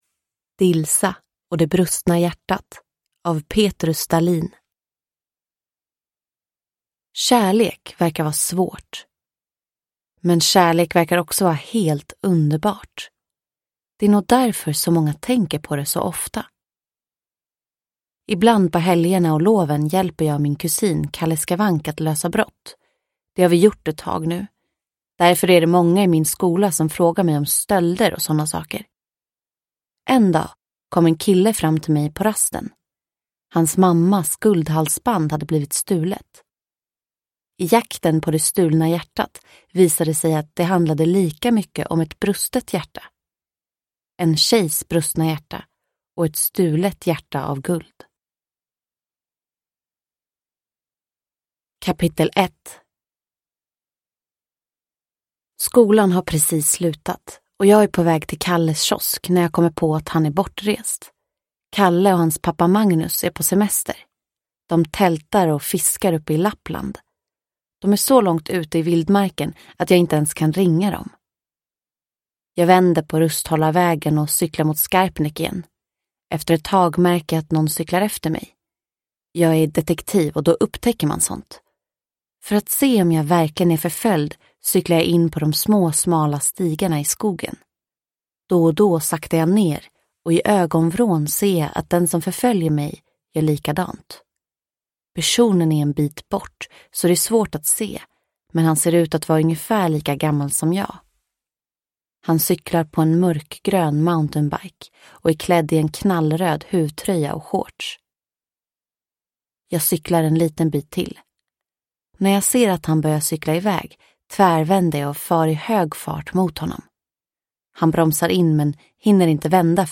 Dilsa och det brustna hjärtat – Ljudbok – Laddas ner